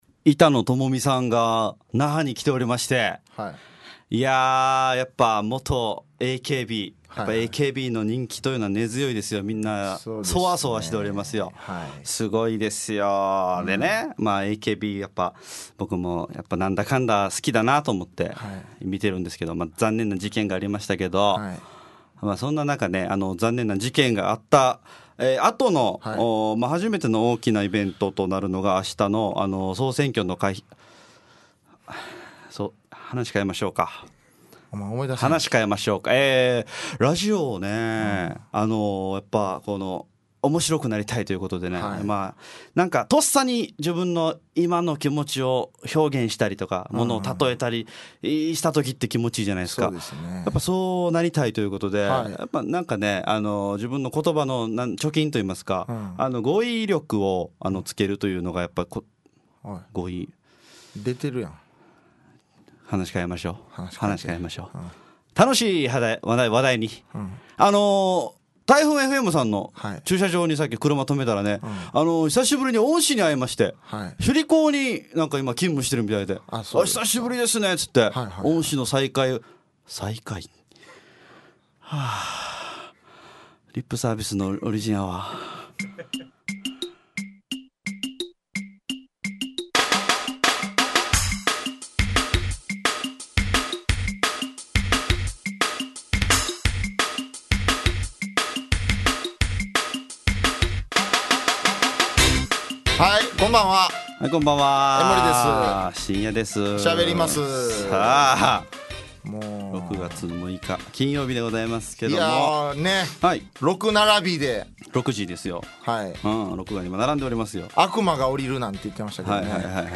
沖縄のFMラジオ局 fm那覇。